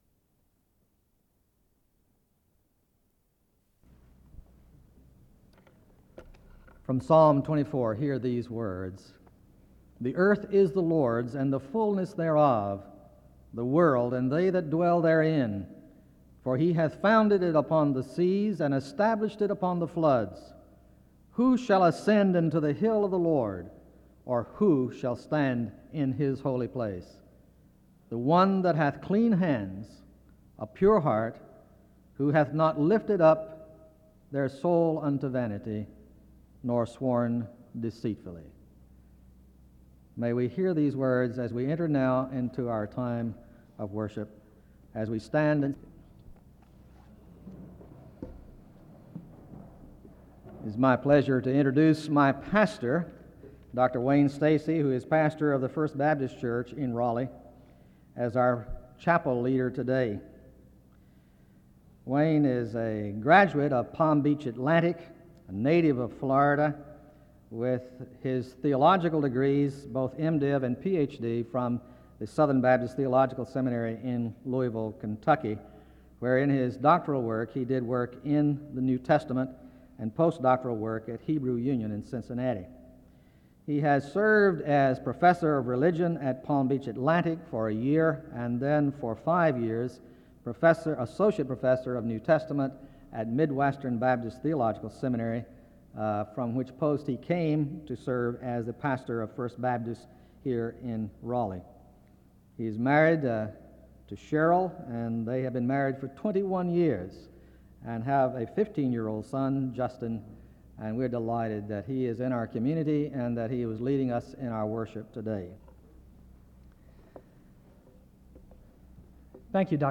The audio was transferred from audio cassette.
Location Wake Forest (N.C.)